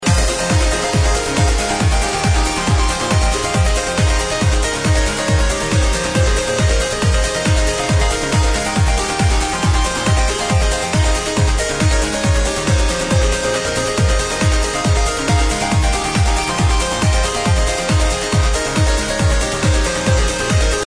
Arrow unknown melodic trance